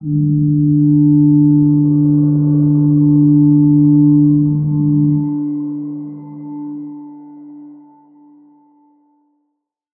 G_Crystal-D4-f.wav